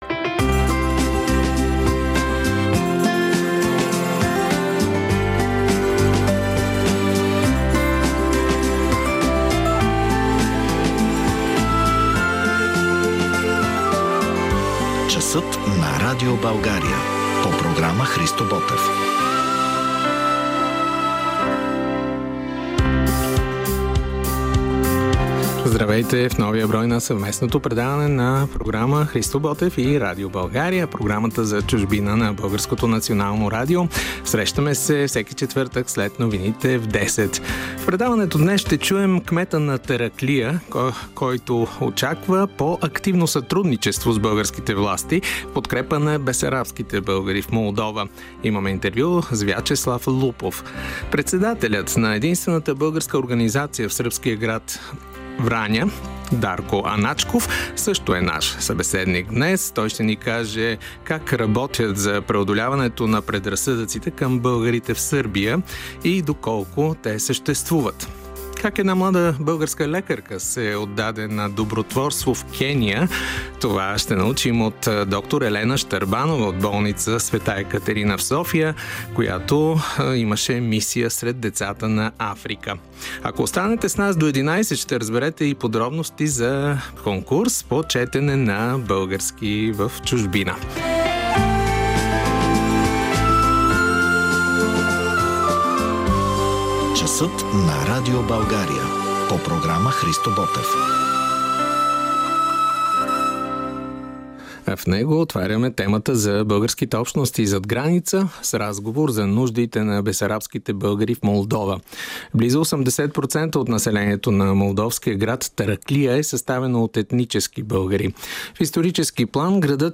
🟠 Кметът на Тараклия Вячеслав Лупов за очакванията си българските власти да сътрудничат по-активно в подкрепа на бесарабските българи в Молдова.